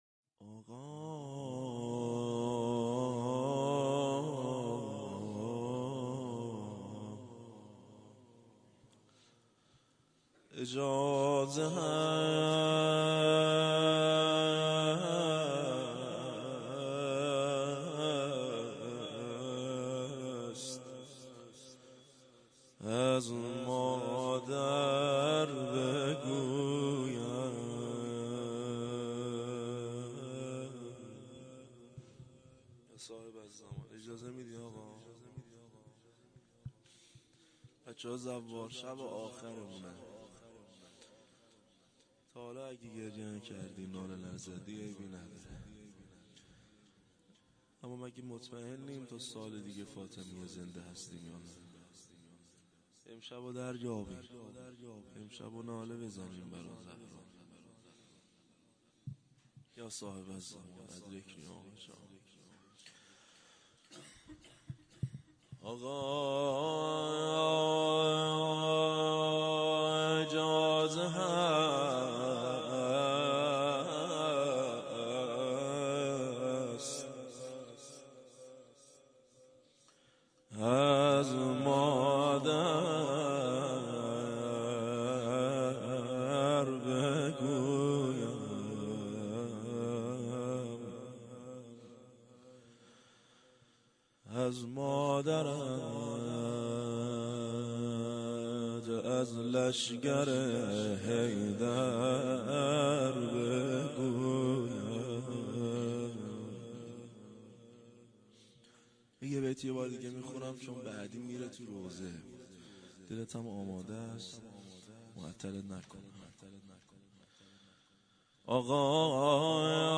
هیئت زواراباالمهدی(ع) بابلسر
0 0 روضه حضرت صدیقه طاهره (س)
شب سوم ویژه برنامه فاطمیه دوم ۱۴۳۹